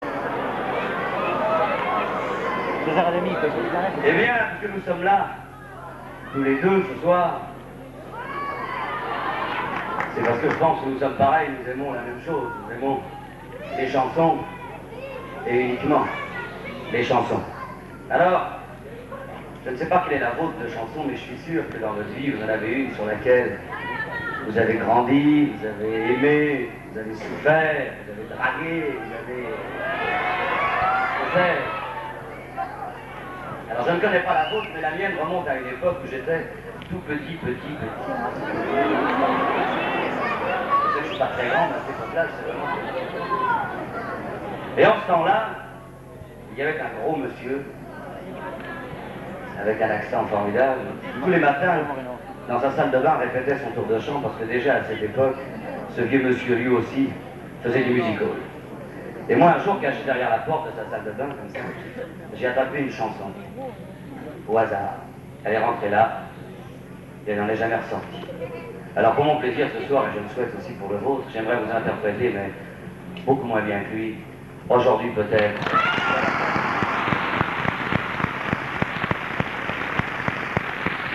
Bootlegs (enregistrements en salle)